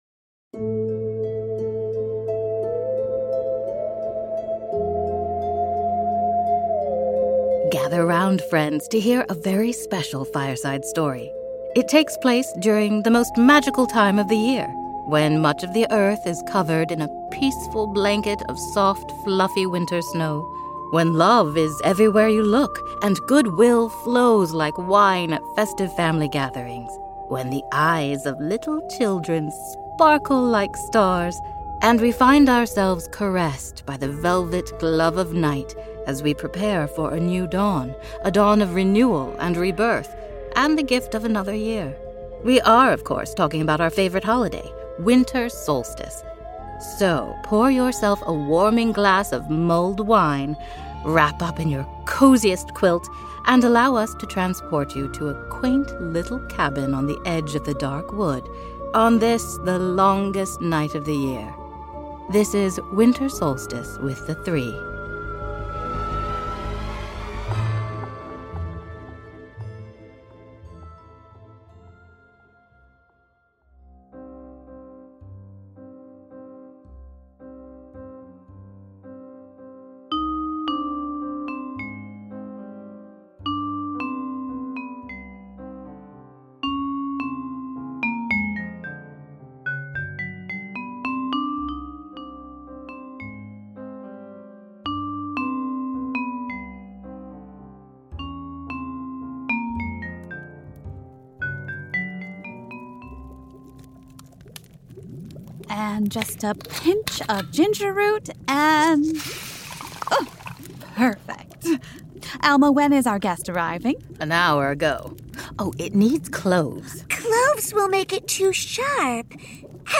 Additional music